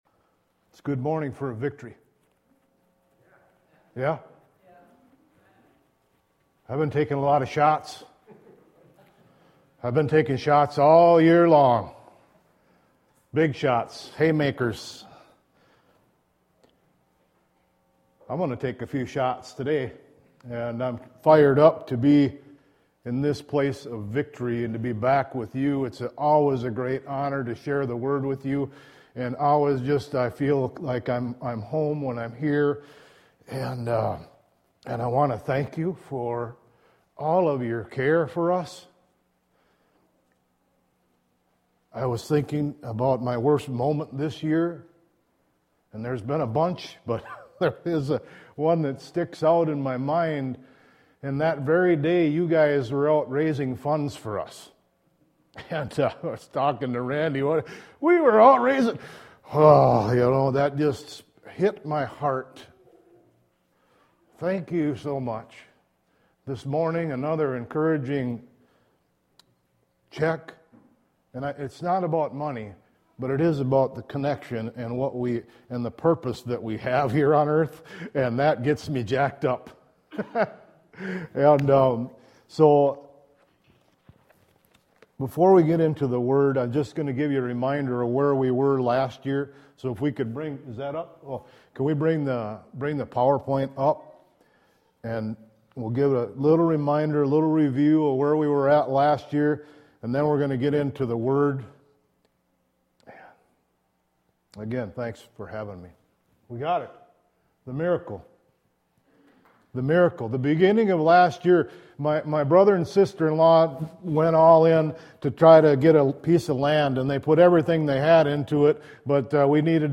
Hear an encouraging and challenging message from guest missionary speaker